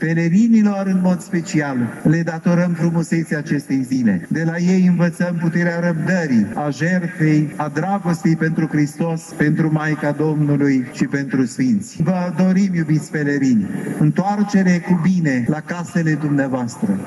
Ceremonialul s-a desfășurat, ca în fiecare an, pe un podium special amenajat pe pietonalul Ștefan cel Mare și Sfânt, în dreptul Catedralei Mitropolitane.